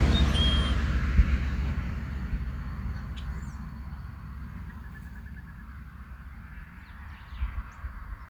Cardeal-do-banhado (Amblyramphus holosericeus)
Nome em Inglês: Scarlet-headed Blackbird
Condição: Selvagem
Certeza: Observado, Gravado Vocal